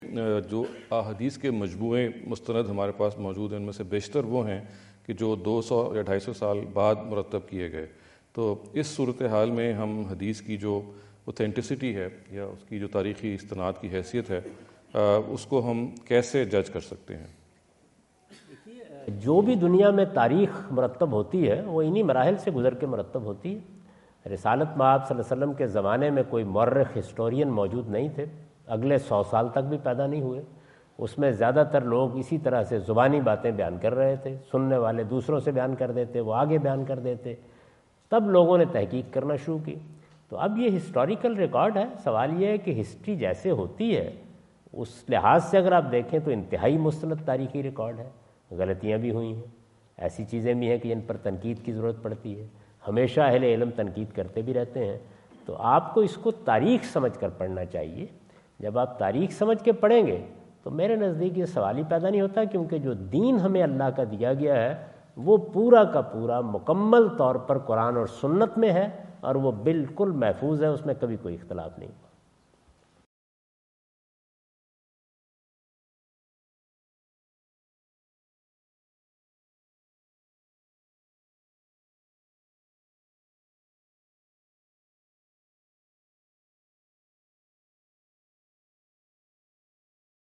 Category: English Subtitled / Questions_Answers /
Javed Ahmad Ghamidi answer the question about "Historical Authenticity of Hadith Collection" asked at The University of Houston, Houston Texas on November 05,2017.